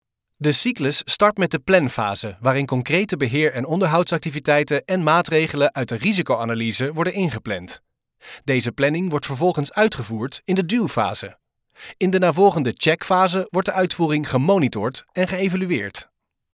Commercieel, Veelzijdig, Vertrouwd, Vriendelijk, Warm
E-learning
Think of a fresh, mature voice with that typical millennial sound: clear, accessible and fresh.